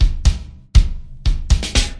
高音萨克斯独奏Loop 1
标签： 120 bpm Dance Loops Woodwind Loops 2.78 MB wav Key : Unknown
声道立体声